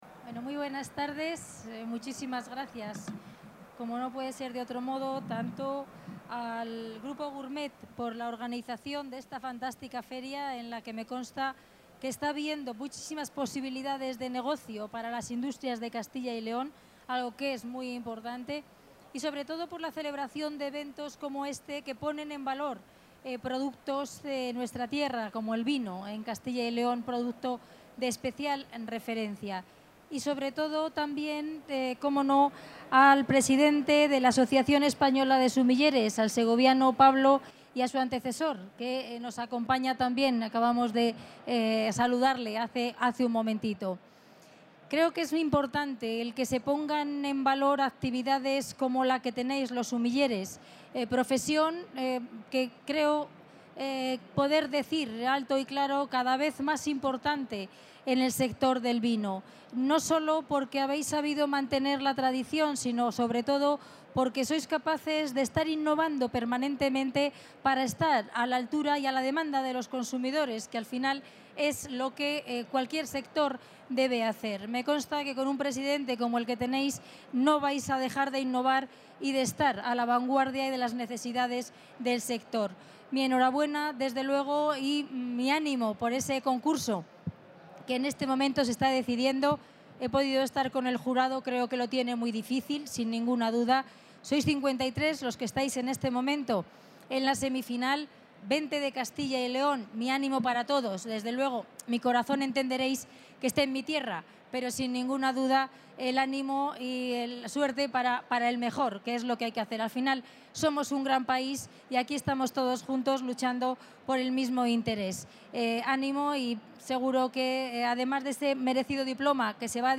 La consejera de Agricultura y Ganadería, Milagros Marcos, presenta en el marco del XXII Campeonato de España de Sumilleres Glorioso, que se celebra en el 30 Salón de Gourmets, la I Jornada Zarcillo que contará con ponentes de prestigio dentro del sector, tanto a nivel nacional como internacional, y con expertos conocedores en materia vitivinícola, de promoción y mercados.